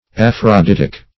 Aph`ro*dit"ic\
aphroditic.mp3